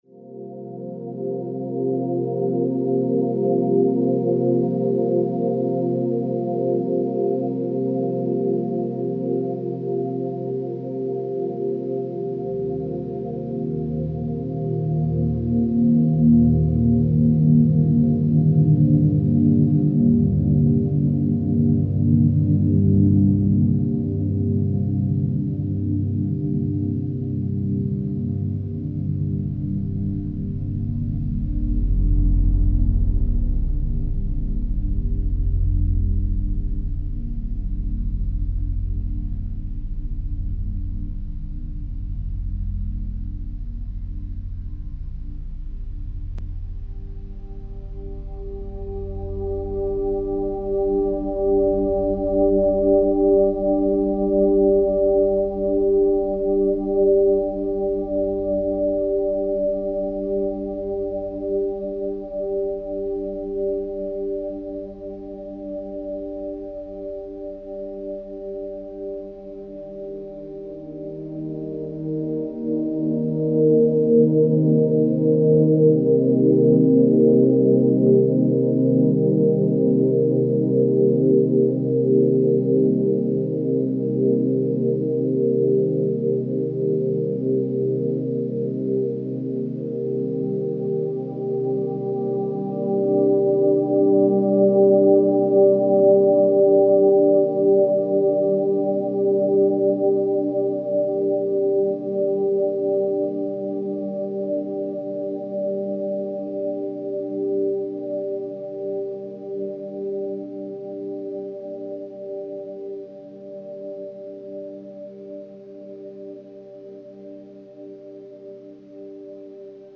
slow and chilled out trademark sound
Deep atmospheric dream themes is the tone.